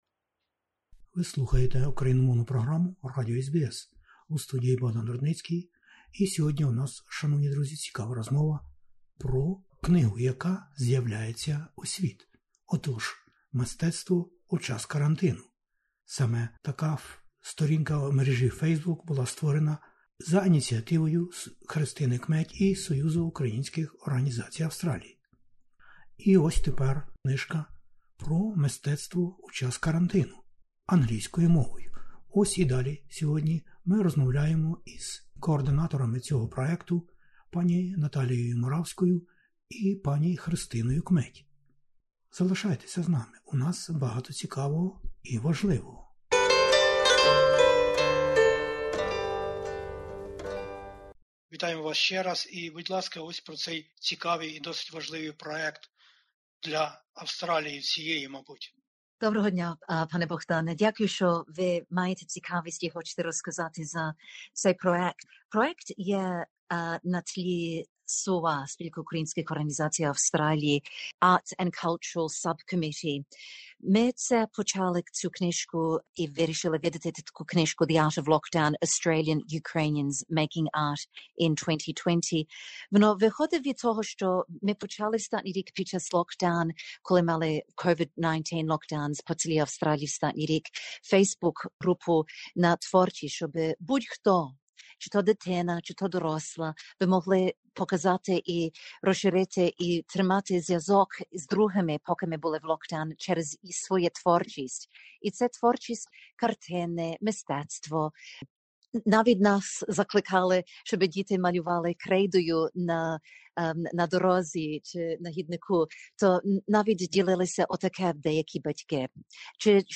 Саме про неї ми і розмовляємо на хвилях SBS Ukrainian